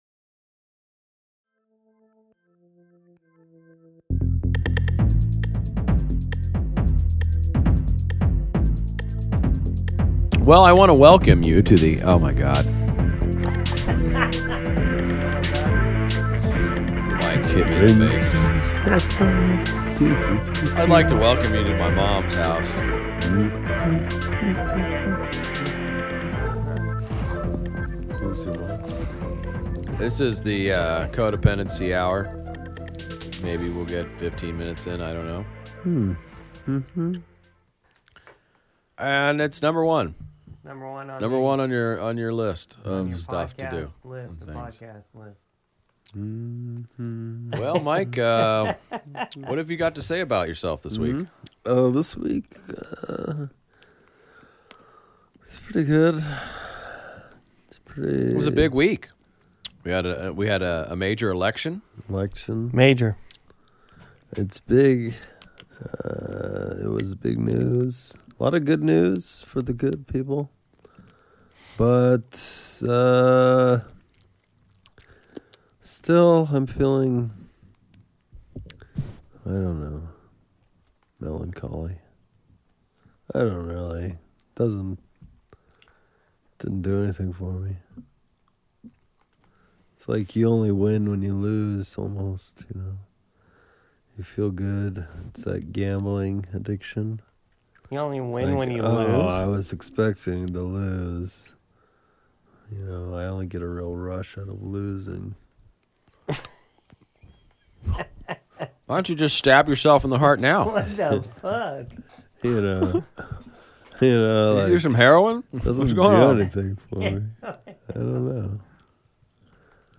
Just three working class gentlemen trying to get through it.
Genres: Comedy